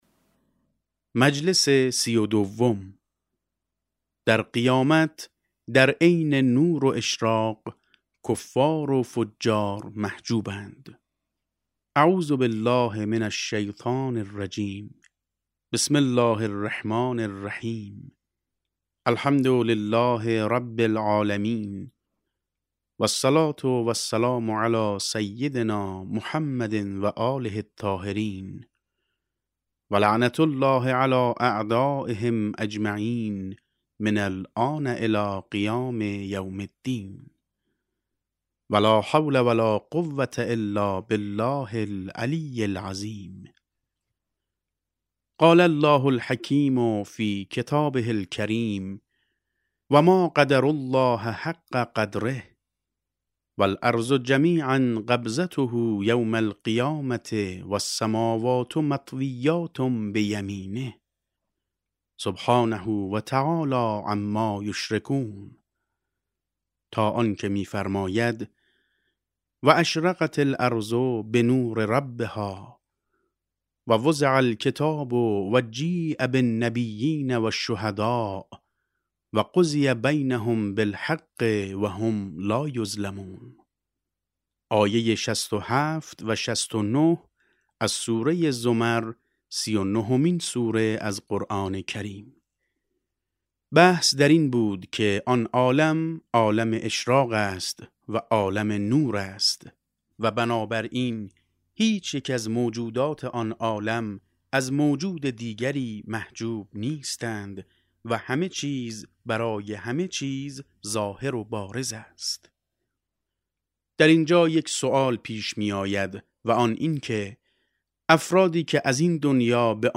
کتاب صوتی معاد شناسی ج5 - جلسه7